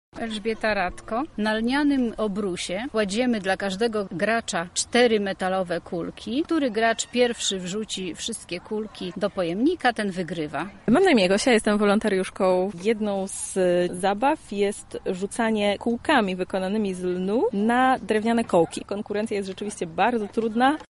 Podczas wydarzenia odwiedzający muzeum mieli możliwość uczestnictwa w dawnych grach i zabawach – również z wykorzystaniem lnu:
relacja